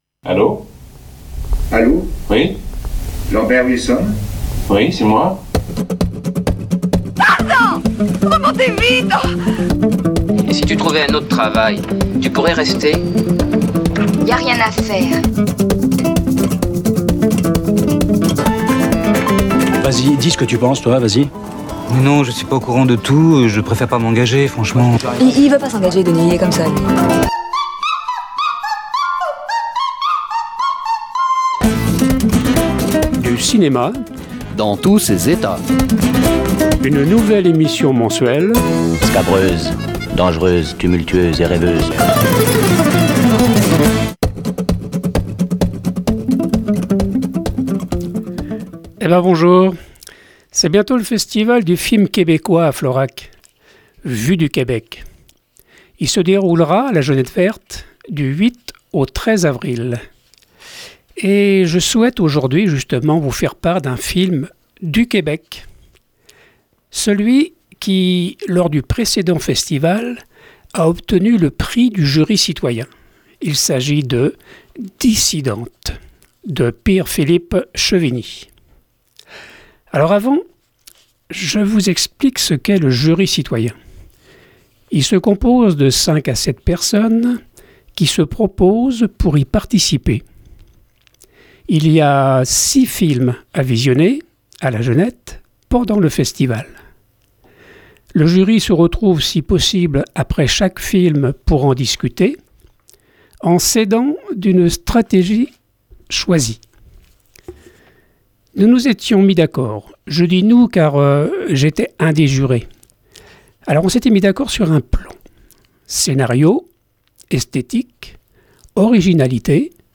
Accompagné de quelques chansons québécoises.